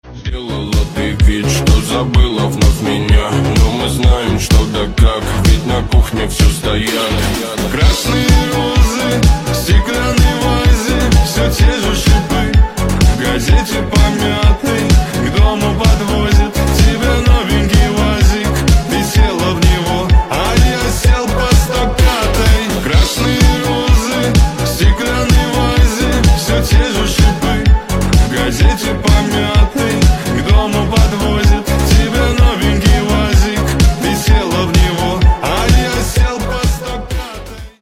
Рингтоны шансон , Mashup
Нейросеть